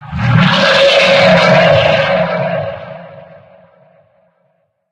main Divergent / mods / Soundscape Overhaul / gamedata / sounds / monsters / bloodsucker / attack_2.ogg 26 KiB (Stored with Git LFS) Raw Permalink History
attack_2.ogg